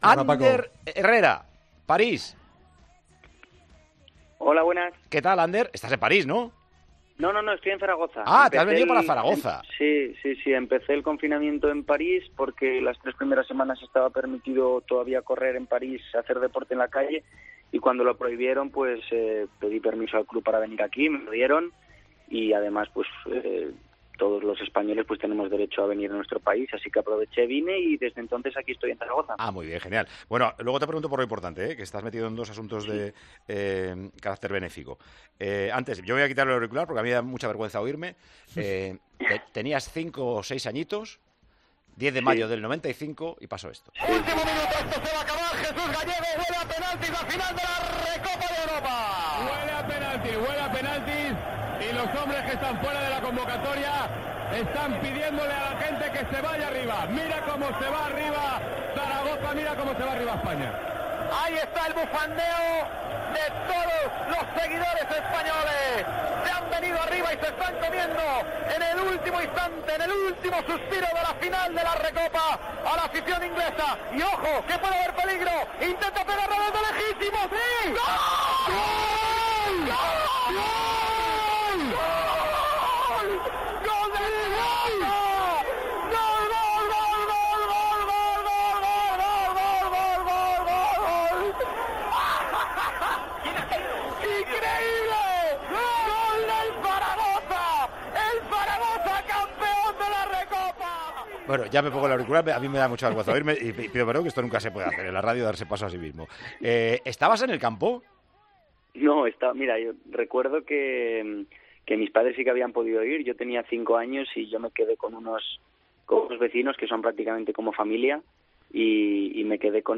El centrocampista del PSG, Ánder Herrera, ha pasado este domingo por los micrófonos de Tiempo de Juego .